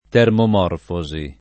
vai all'elenco alfabetico delle voci ingrandisci il carattere 100% rimpicciolisci il carattere stampa invia tramite posta elettronica codividi su Facebook termomorfosi [ t H rmomorf 0@ i ; alla greca termom 0 rfo @ i ] s. f. (bot.)